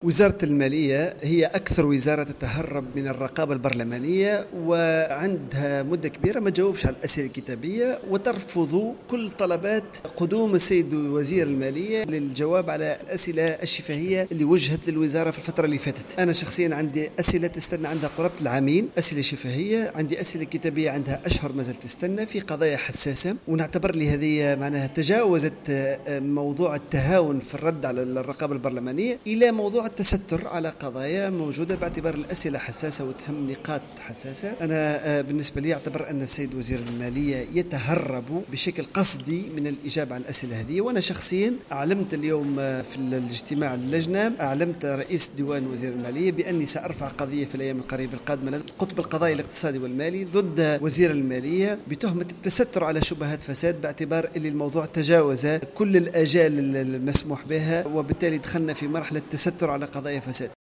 وأوضح الدايمي في تصريح للجوهرة أف أم أن هذه القضية على خلفية ما وصفه بتهرب الوزير من الإجابة عن أسئلة شفاهية كان قد طرحها منذ سنتين عن مآل الذهب والمعادن النفيسة التي يتم حجزها من قبل مصالح الديوانة ، مضيفا أنه سيتوجه إلى القطب القضائي المالي و الإداري لإيداع شكاية بشبهة التستر عن ملفات فساد .